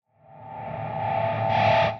Action Stations (Atmos 01) 120BPM.wav